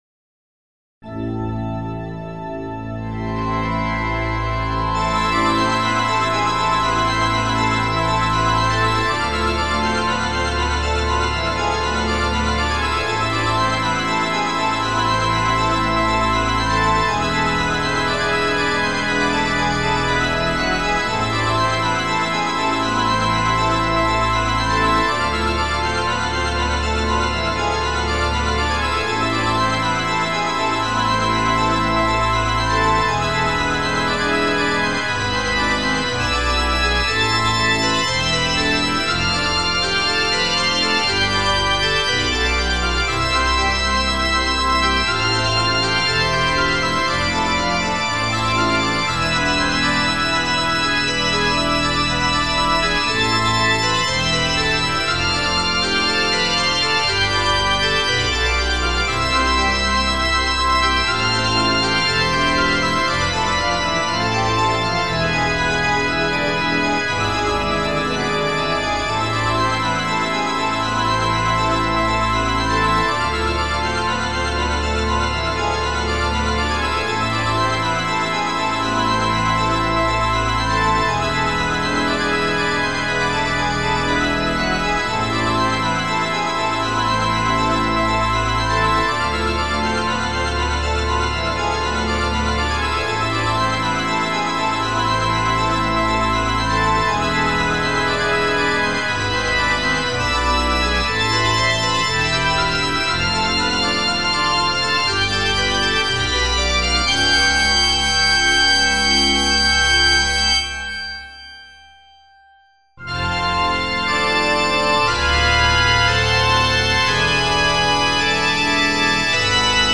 クラシックファンタジー暗い